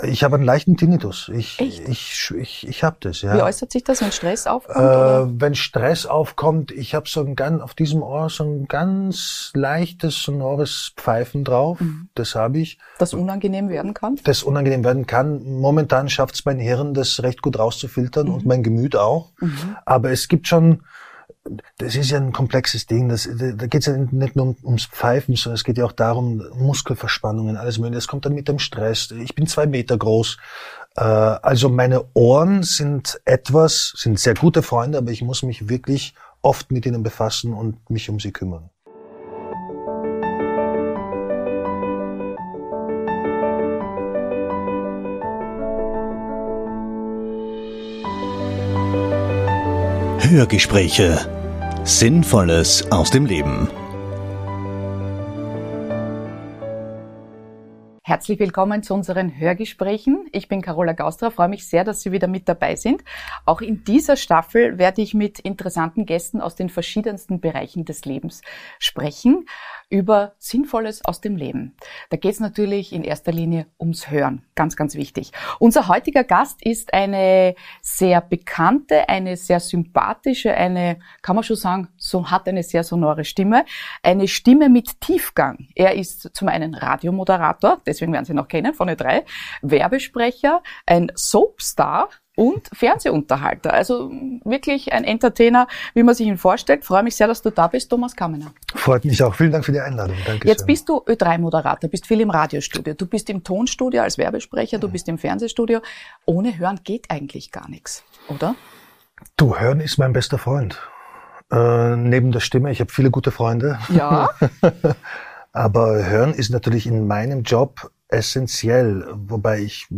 Was es genau damit auf sich hat, erfährt man spannenden Interview.